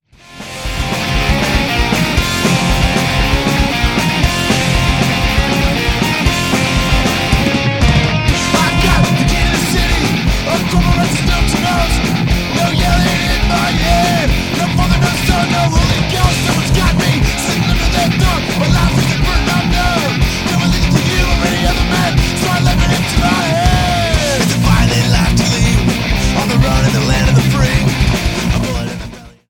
Thirteen in your face punk rock gems.